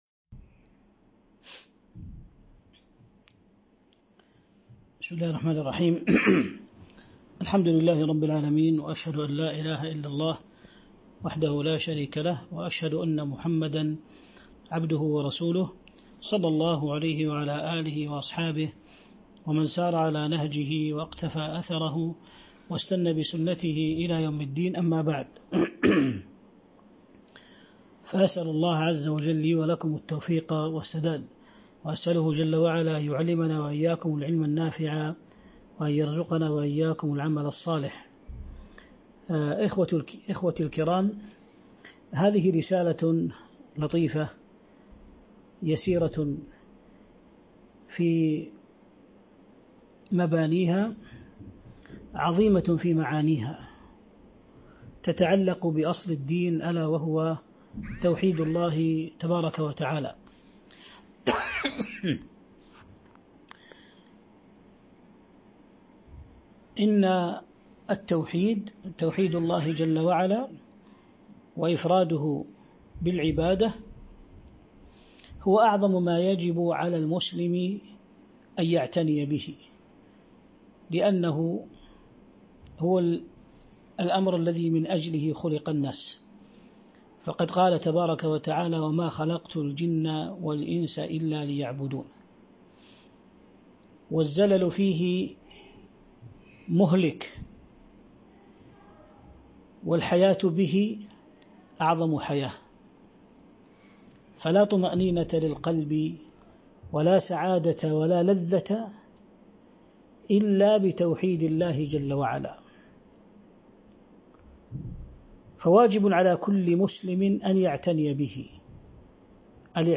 شرح القواعد الأربع عبر البث المباشر 1441 هـ